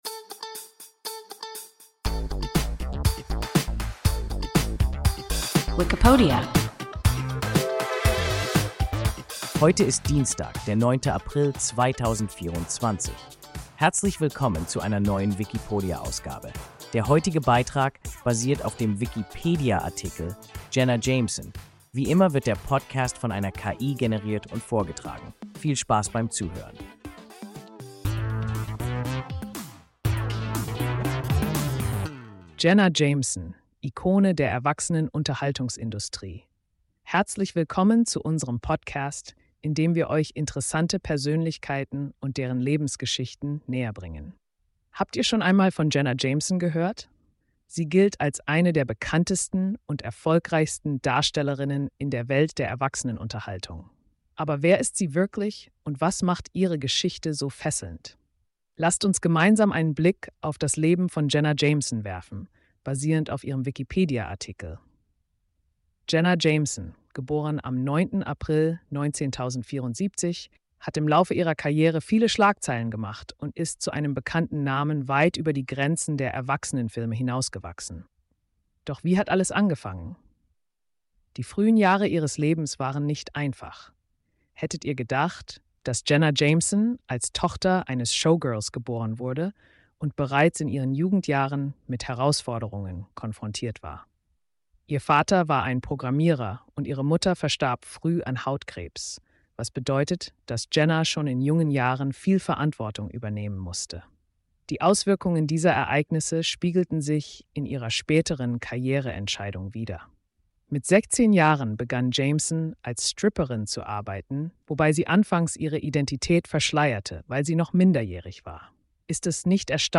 Jenna Jameson – WIKIPODIA – ein KI Podcast